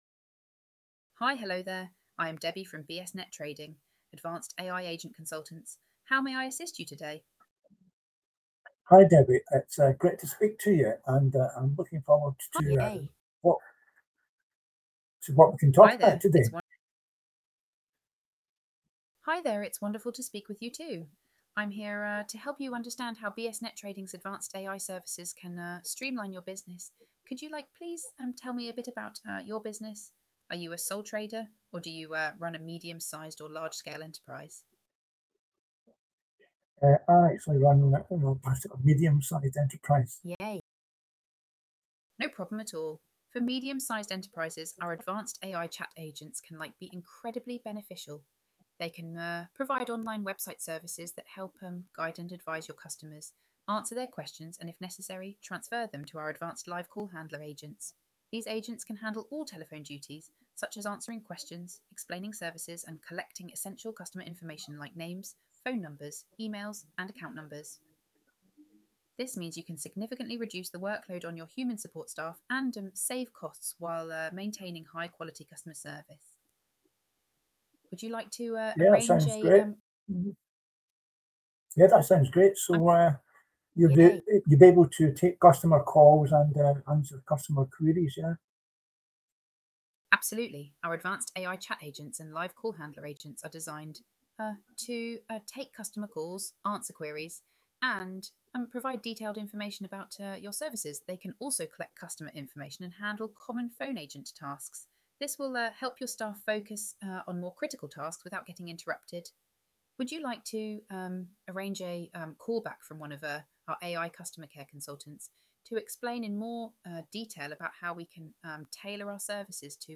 Listen to one of our AI agents taking a call this could be your business sound professional with our advanced AI Call handlers click here to listen.